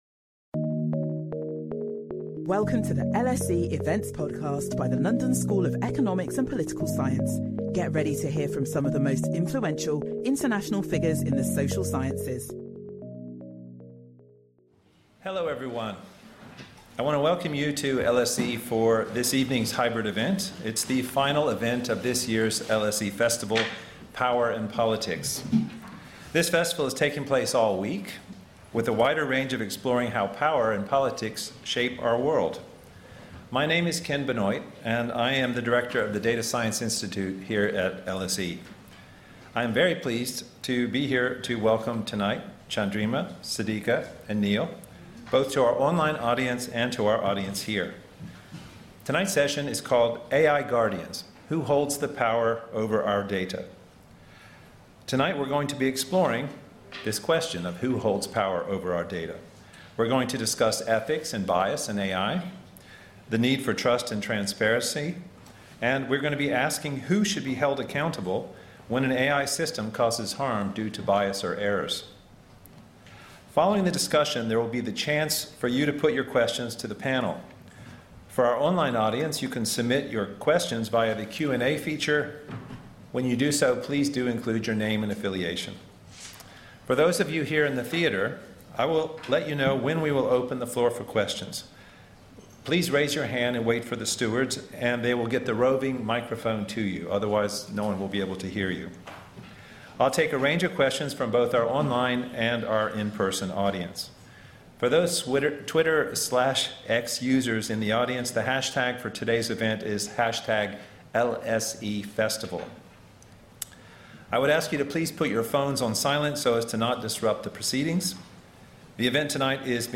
Our panel explore the ethics and bias in AI and why diverse and inclusive data teams and decision-makers are needed to shape the algorithms and models of the future.